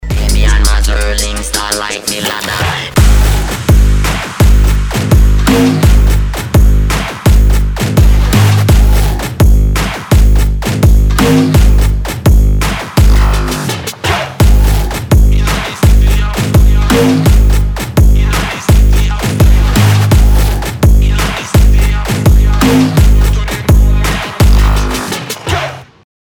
• Качество: 320, Stereo
мужской голос
ритмичные
громкие
dance
EDM
Trap
Bass
бодрые
Классные басы, классный трэпчик